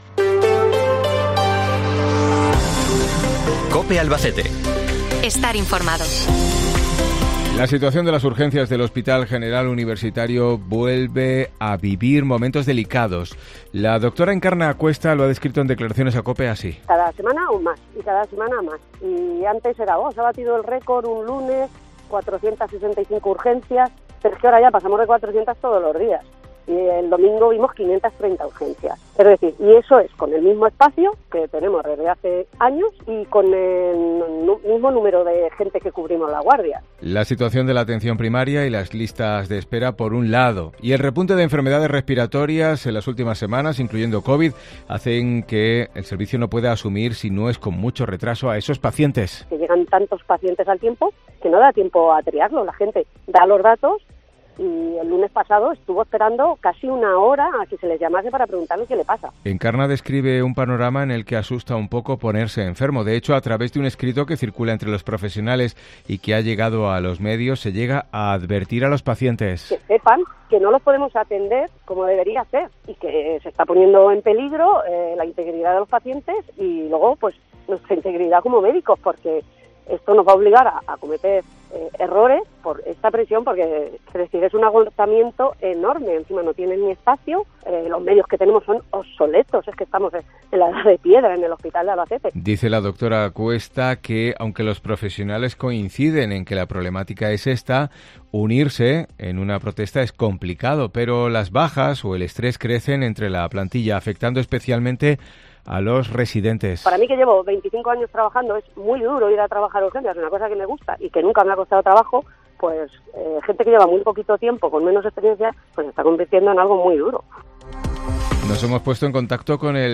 La situación en Urgencias del Hospital General abre este informativo